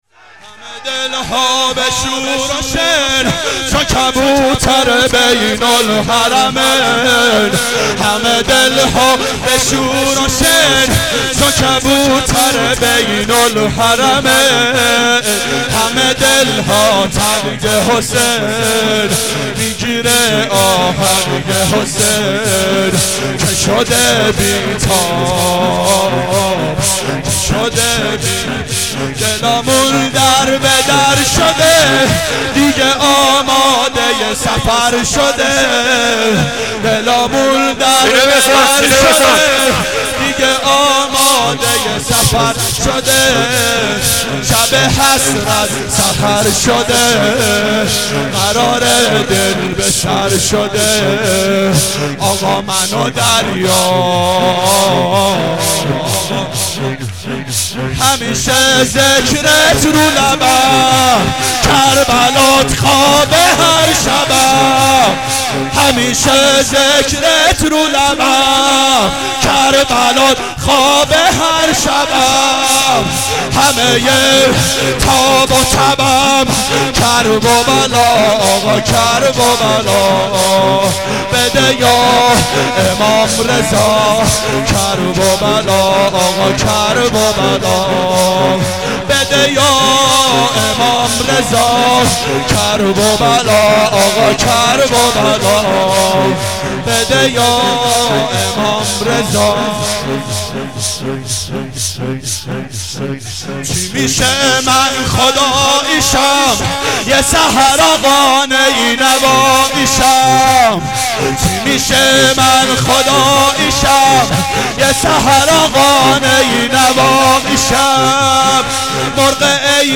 شب سوم محرم 89 گلزار شهدای شهر اژیه